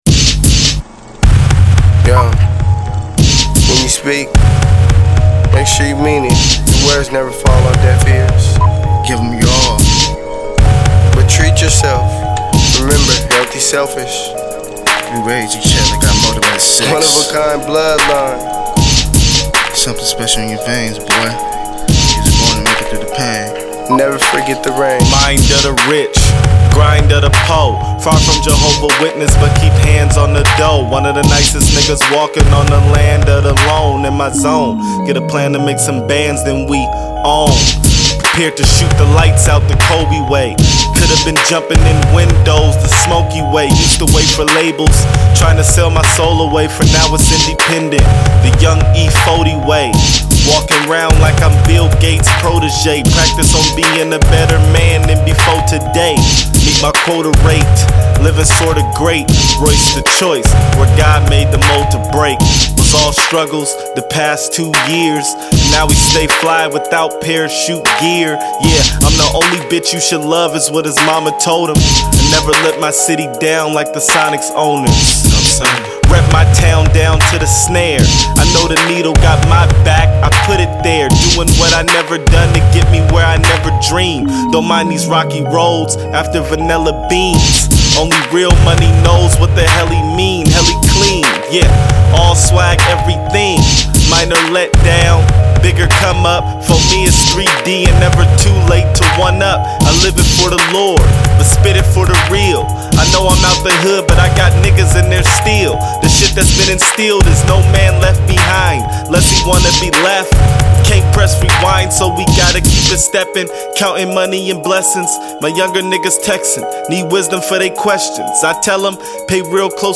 Limited edition rap.